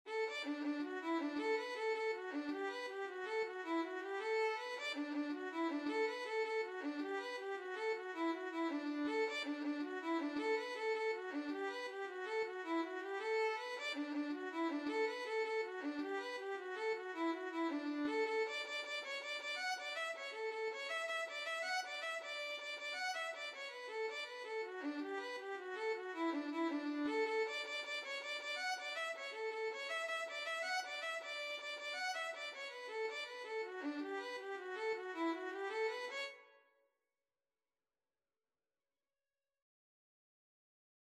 D major (Sounding Pitch) (View more D major Music for Violin )
6/8 (View more 6/8 Music)
D5-F#6
Violin  (View more Easy Violin Music)
Traditional (View more Traditional Violin Music)
Irish